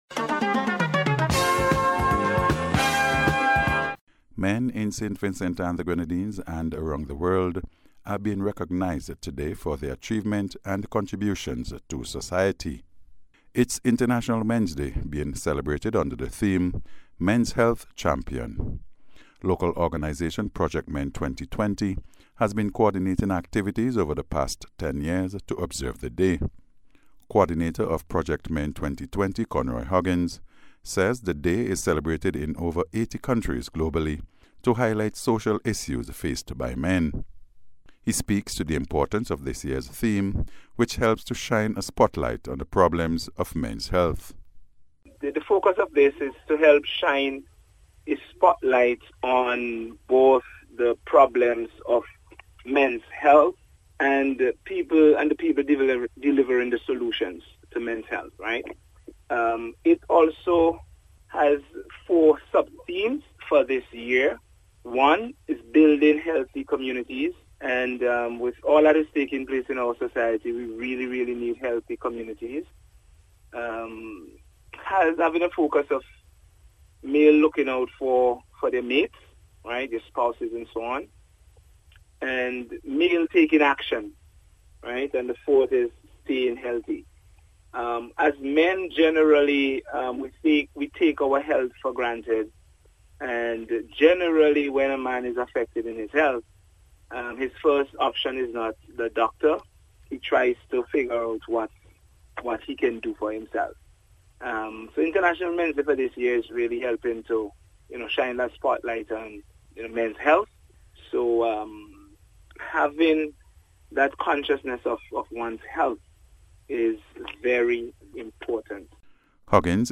INTERNATIONAL-MENS-DAY-REPORT.mp3